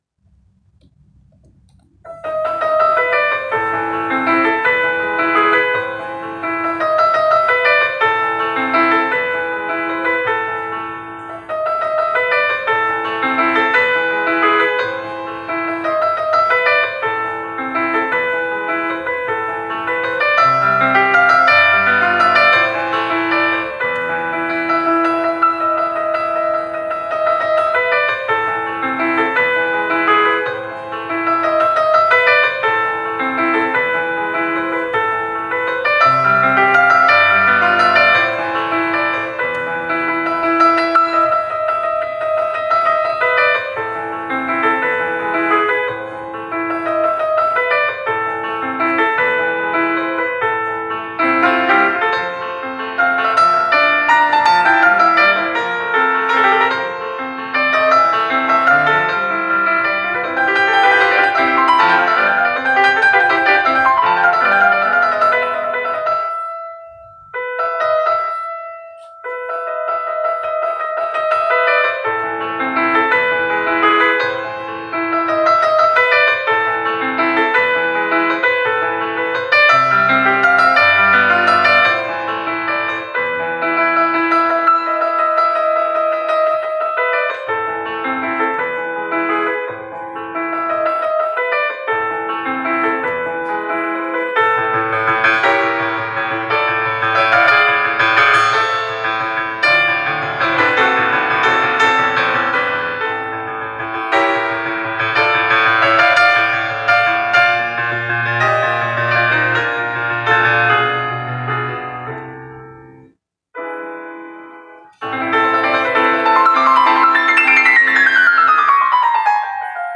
🎼ساعت طرفداری به وقت موسیقی قسمت چهارم/با نوازندگی خودم